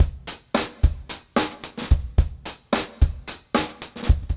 RemixedDrums_110BPM_45.wav